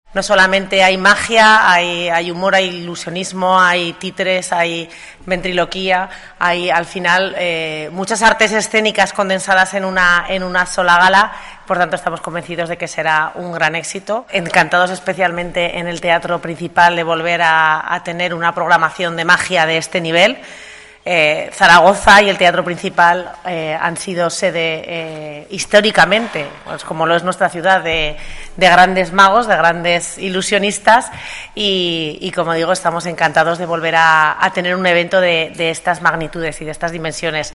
La vicealcaldesa de Zaragoza, Sara Fernández, presenta los detalles del II Festival Internacional de Magia.